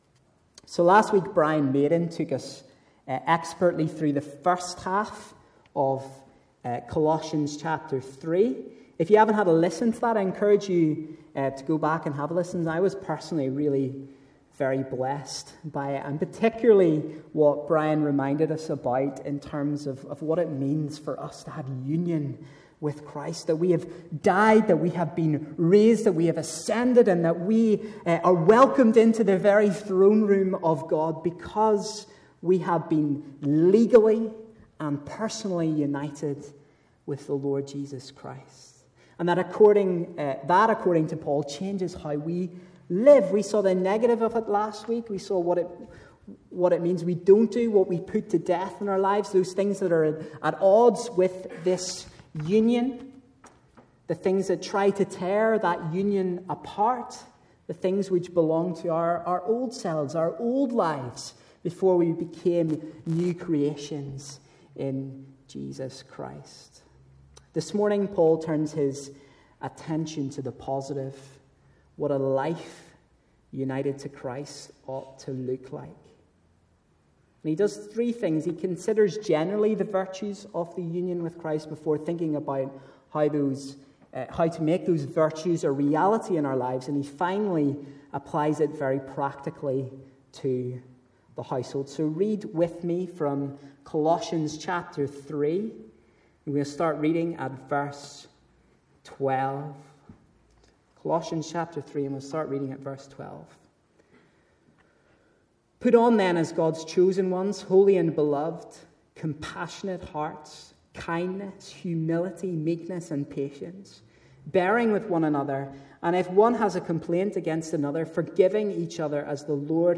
A message from the series "Colossians."